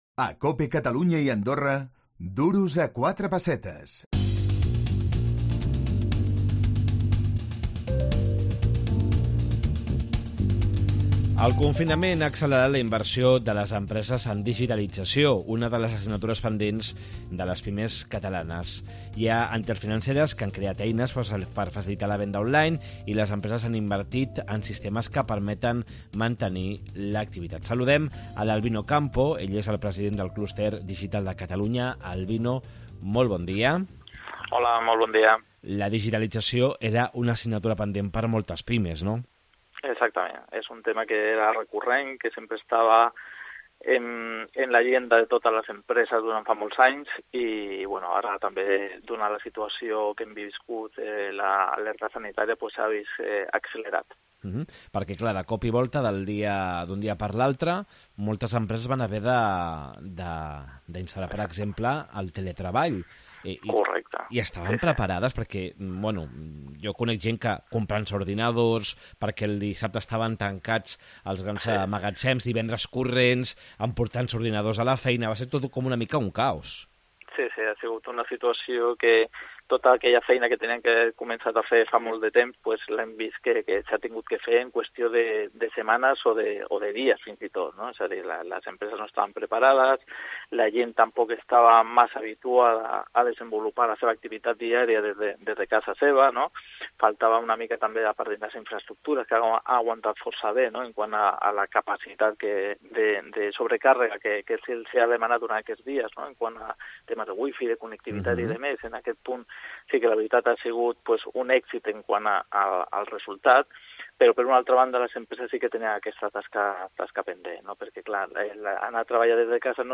Les empreses acceleren la seva digitalització. Entrevista